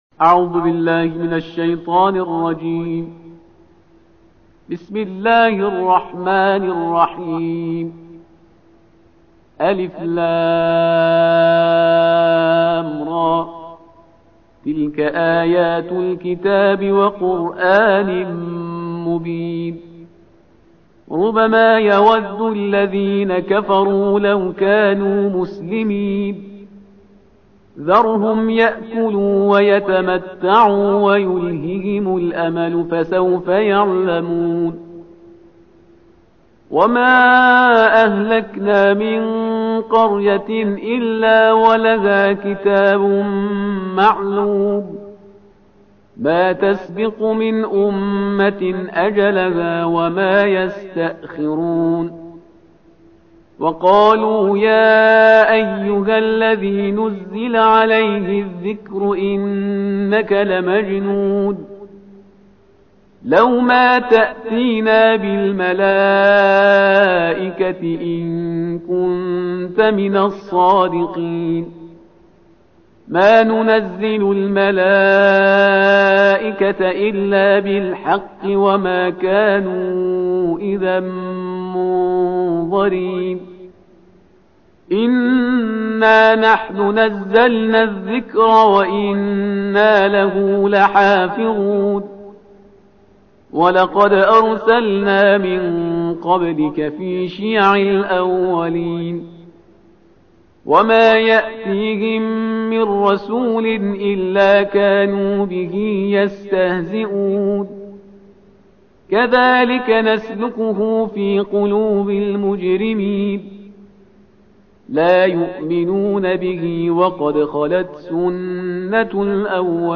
تلاوت ترتیل جزء چهاردهم کلام وحی با صدای استاد